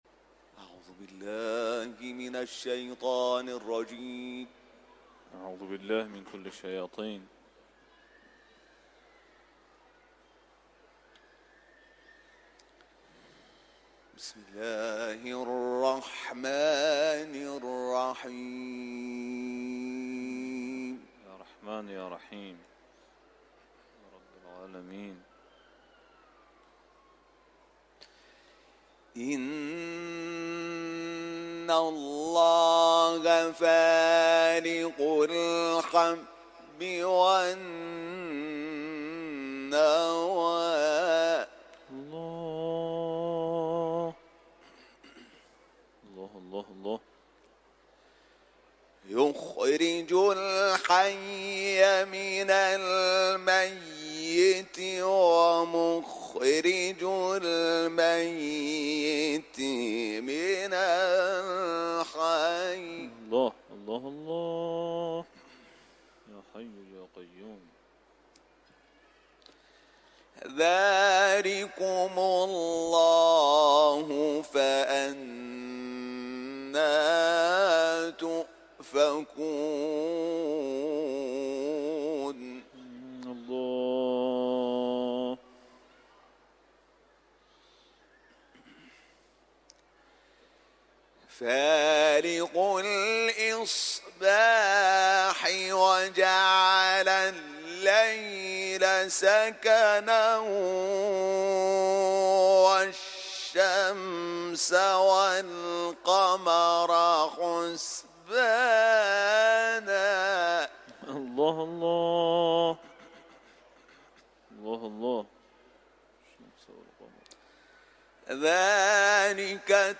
صوت تلاوت آیات ۹۵ تا ۹۸ از سوره «انعام» و نیز آیات سوره‌های «ضحی» و «شرح» با صدای حمید شاکرنژاد، قاری بین‌المللی قرآن که در محفل قرآنی حرم مطهر رضوی اجرا شده است، تقدیم مخاطبان ایکنا می‌شود.
تلاوت